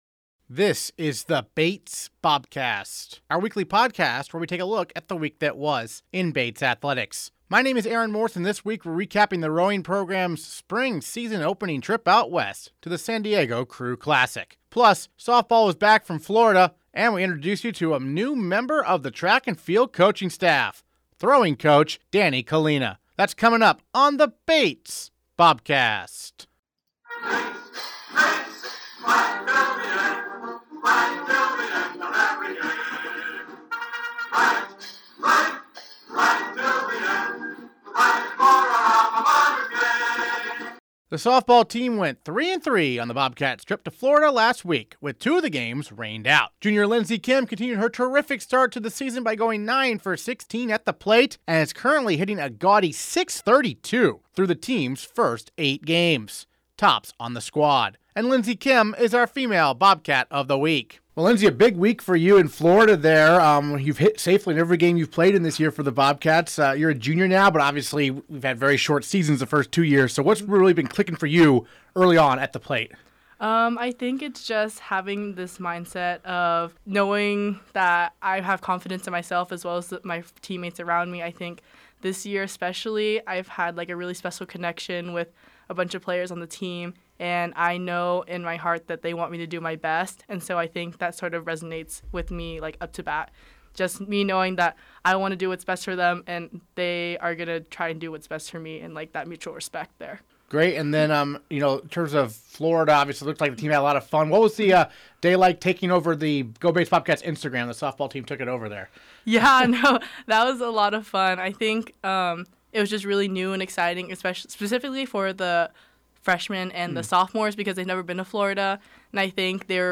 Interviews this episode